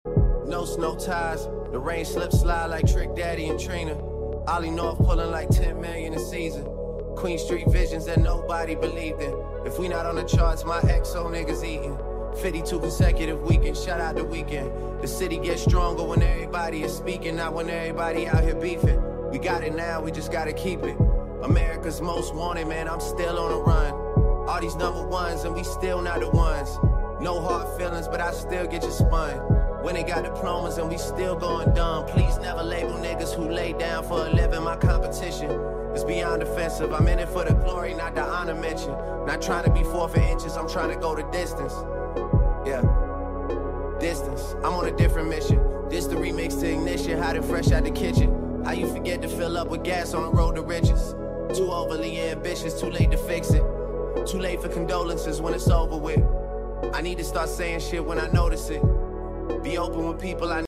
Remix
instrumental